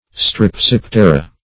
Strepsiptera \Strep*sip"te*ra\, n. pl.